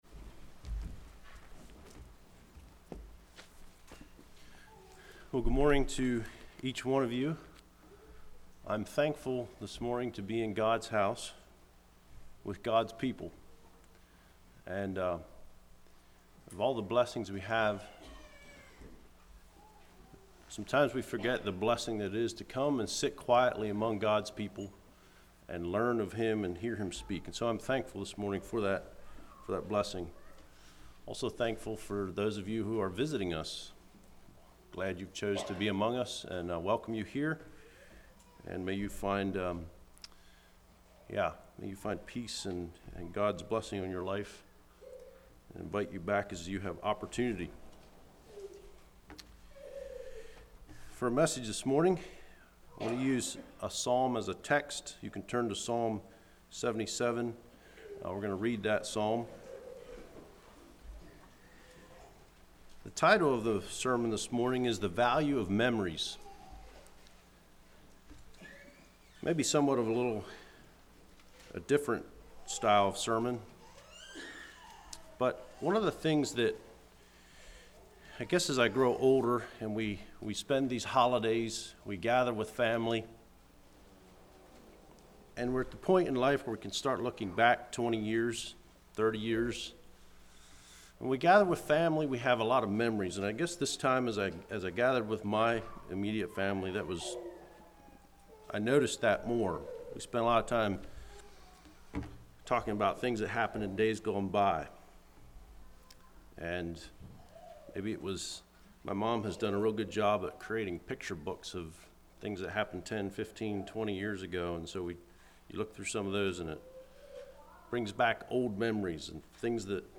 Play Now Download to Device The Value Of Memories Congregation: Blue Ridge Speaker